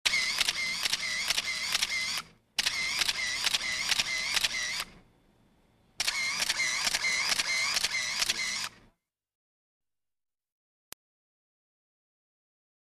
CAMARA CAMARA DE FOTOS
Tonos EFECTO DE SONIDO DE AMBIENTE de CAMARA CAMARA DE FOTOS
camara_camara_de_fotos.mp3